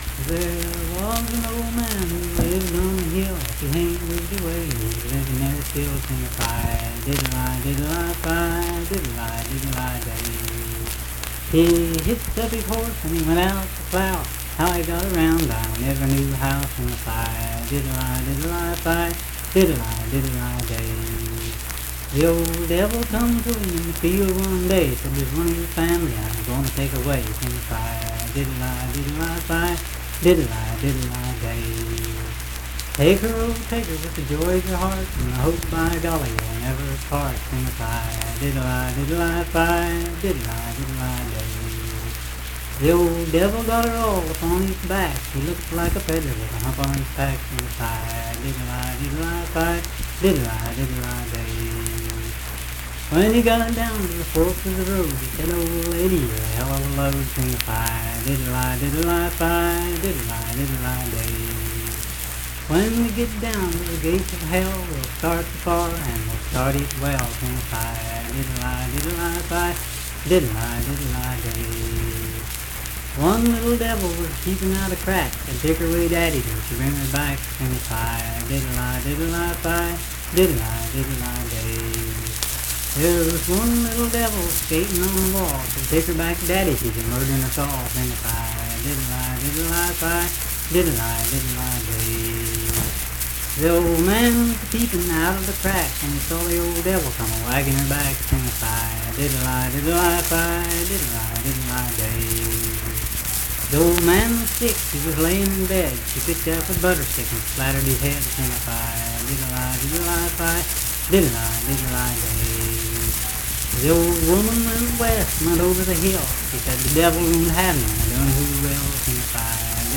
Unaccompanied vocal music
Verse-refrain 13(4w/R).
Performed in Frametown, Braxton County, WV.
Voice (sung)